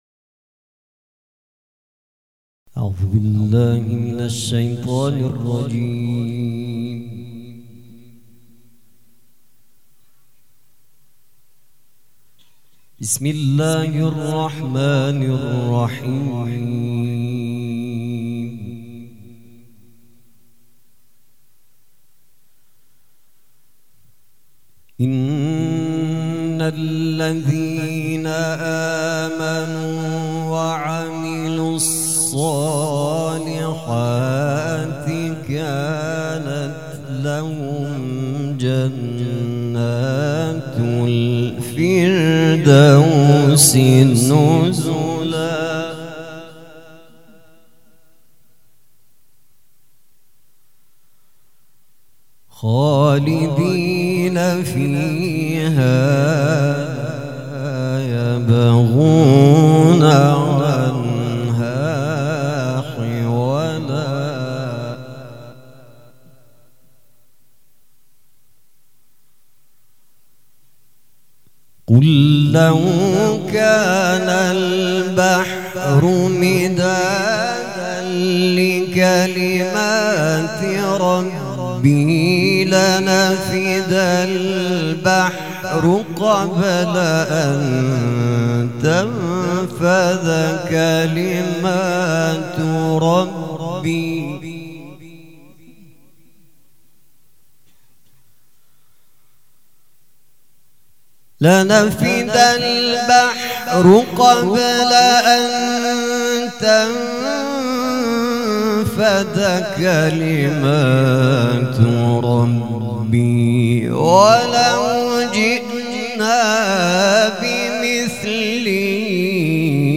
قرائت قرآن
وفات حضرت ام البنین (س)